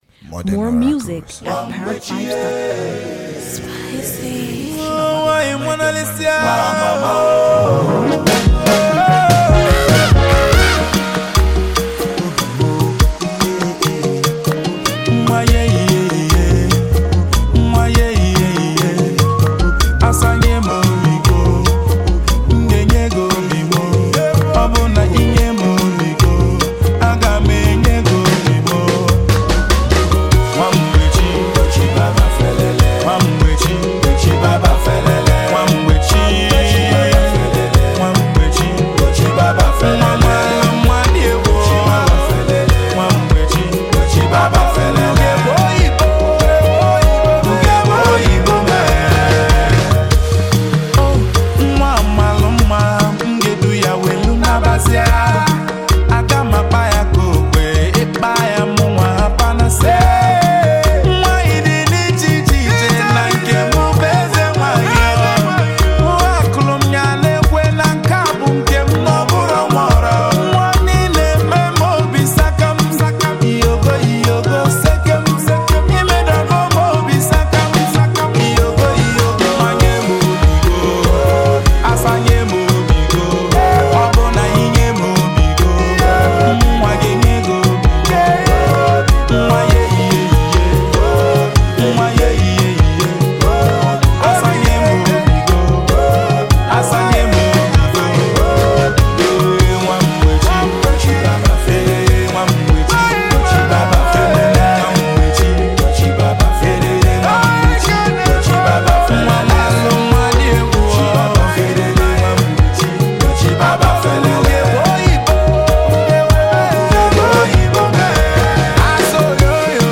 Phenomenally talented Nigerian music duo